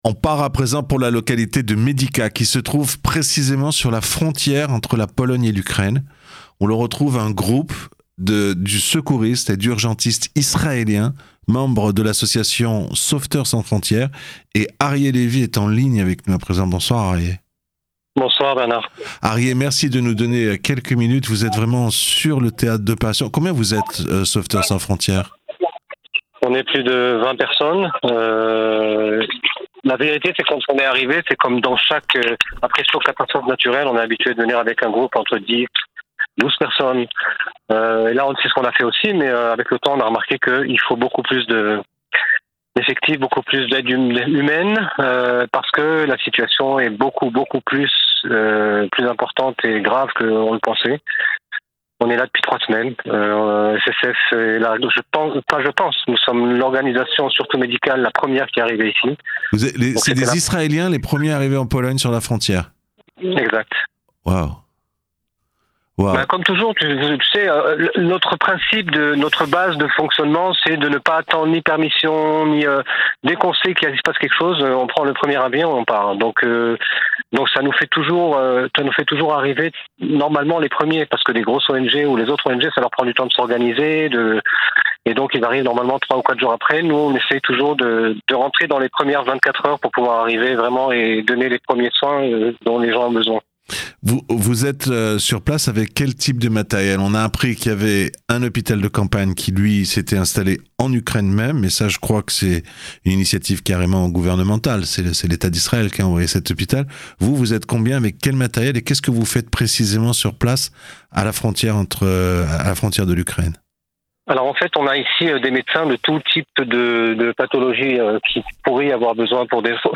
En direct de la frontière ukrainienne avec les Israéliens de Sauveteurs Sans Frontières